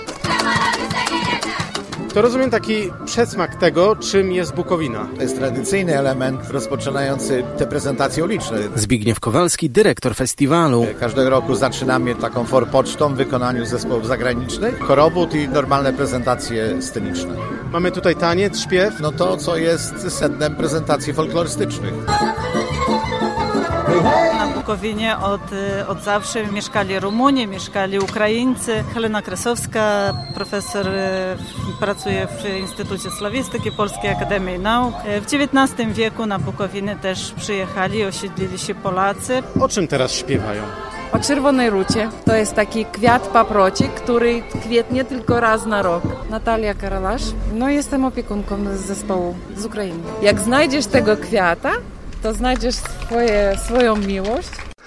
Pierwszy etap odbył się na ulicach Jastrowia, gdzie zespoły z Ukrainy, Rumunii i Węgier prezentowały ludowe pieśni i tańce.